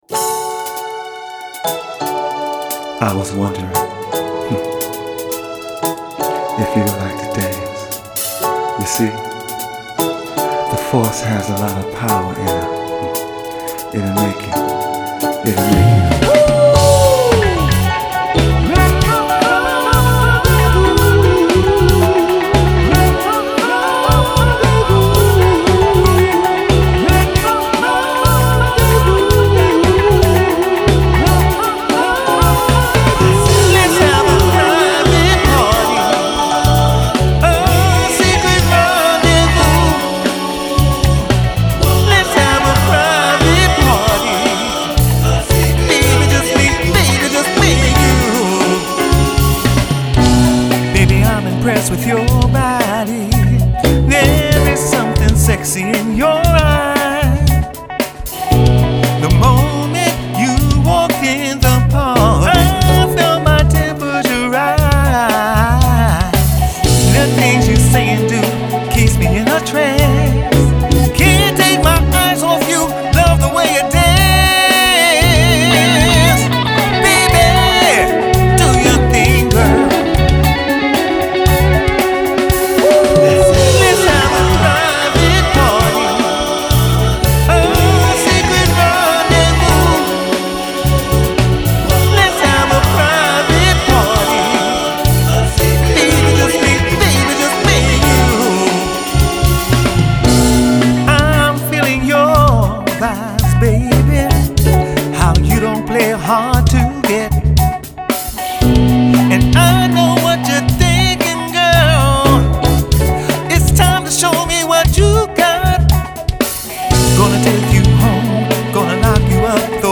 Home > Music > Rnb > Bright > Laid Back > Running